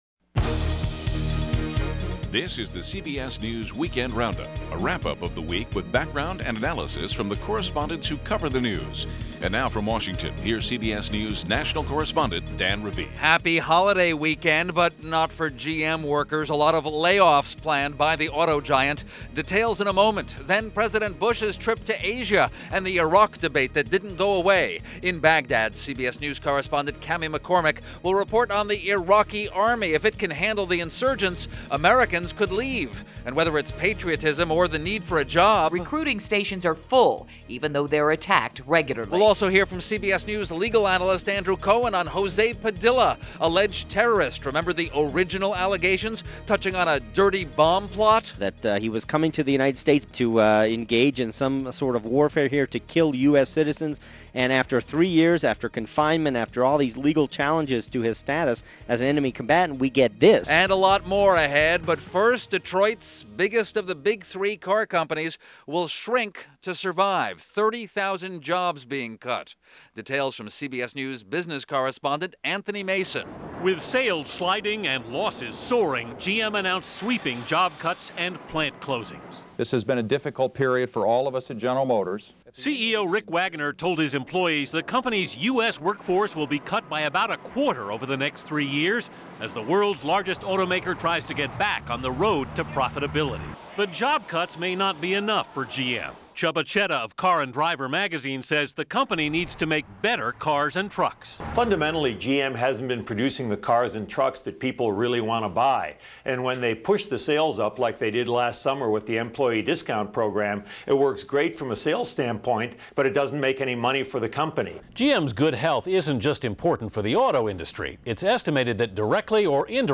GM Layoffs - Bush In Asia - Troops Still In Iraq, But . . . - November 27, 2005 - news for the week ending November 27th.